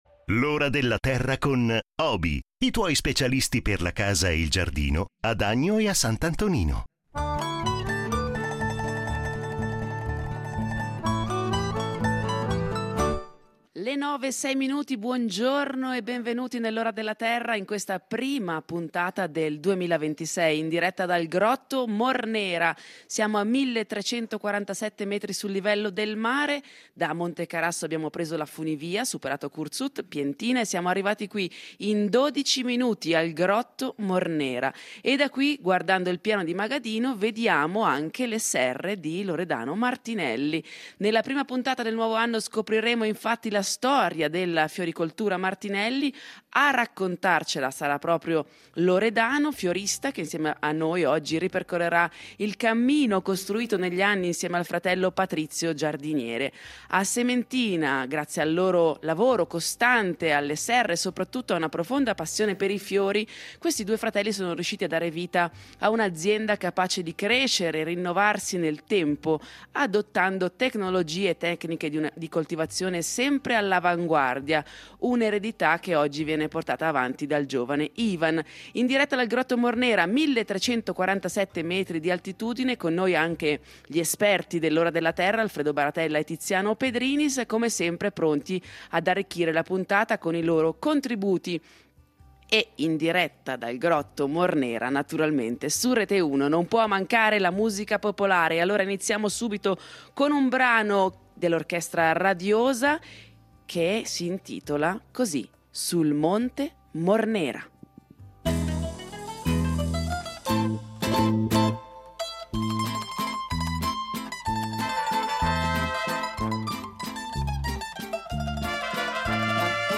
In diretta dal Grotto Mornera, a 1347 metri di altitudine